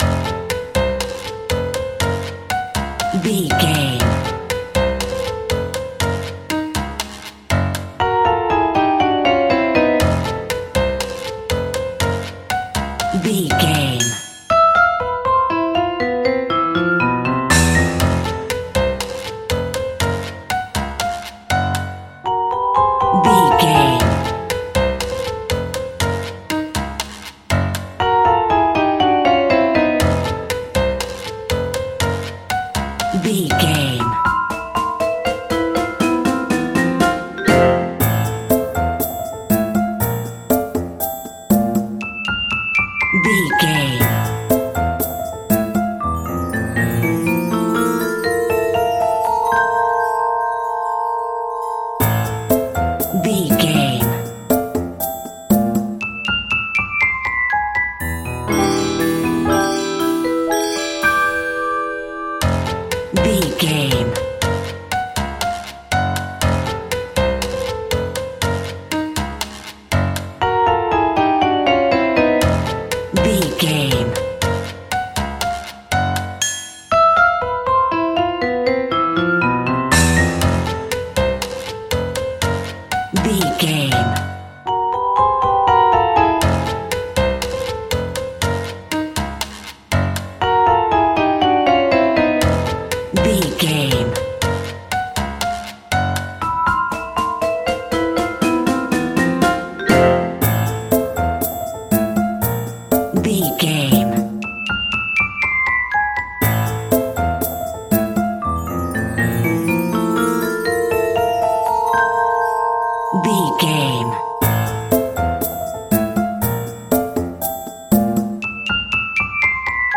Dorian
percussion
piano
silly
circus
goofy
comical
cheerful
perky
warm
Light hearted
secretive
quirky